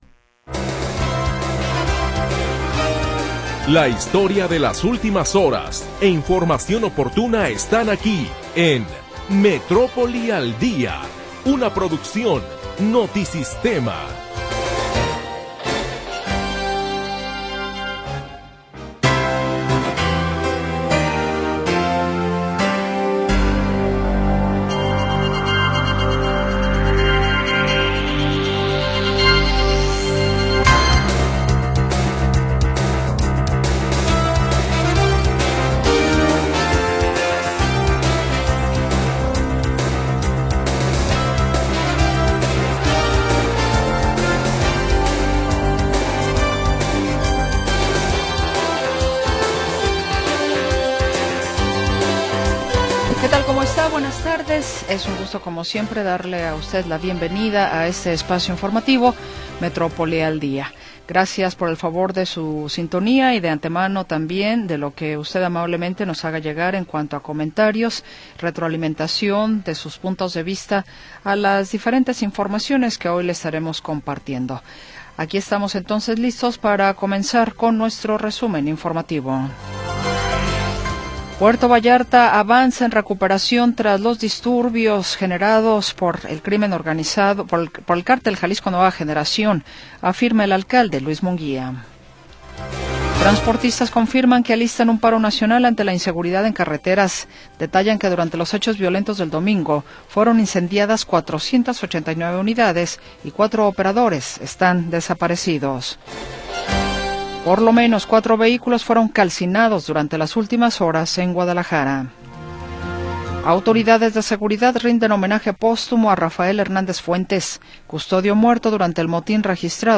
Primera hora del programa transmitido el 26 de Febrero de 2026.